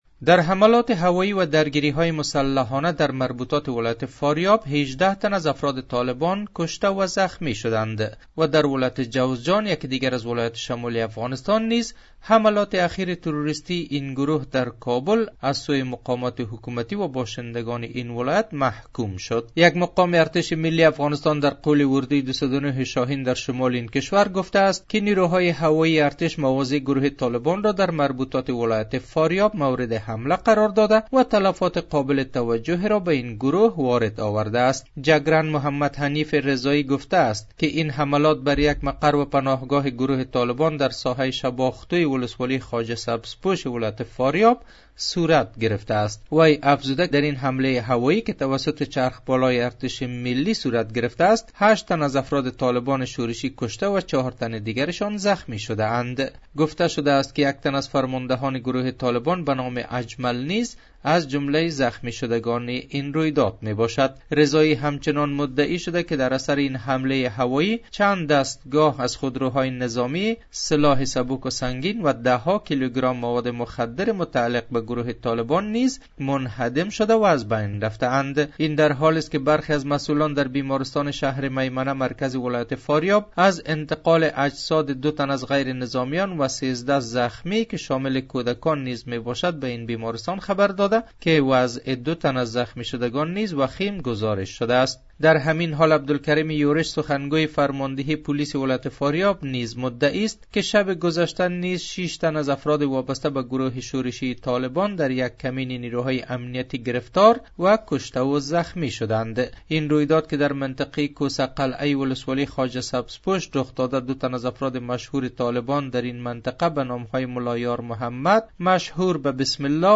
گزارش : تلفات طالبان در ولایت فاریاب در عملیات نیروهای افغان